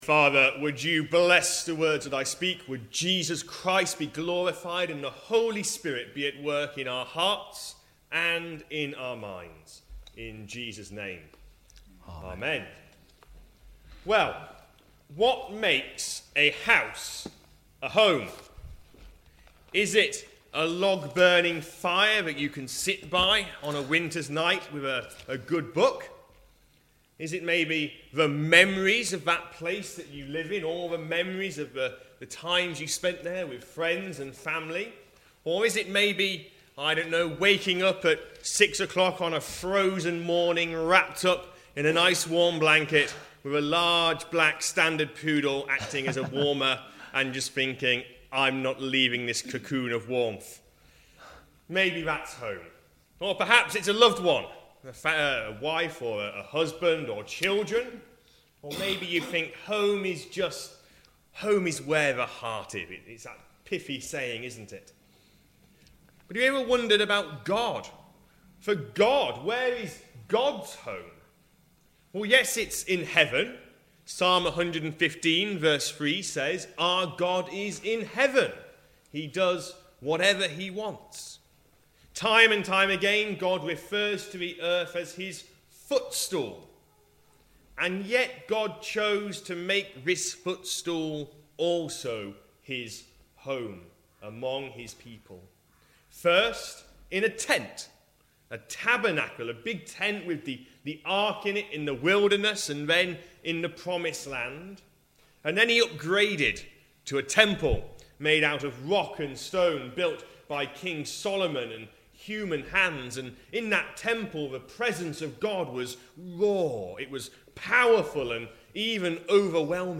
From our sermon series